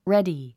発音
rédi　レディ